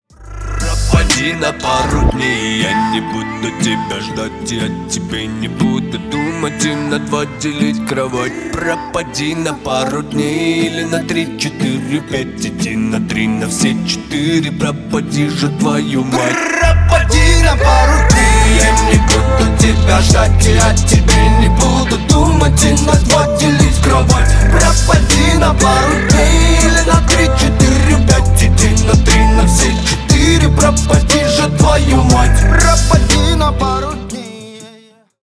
Поп Музыка # спокойные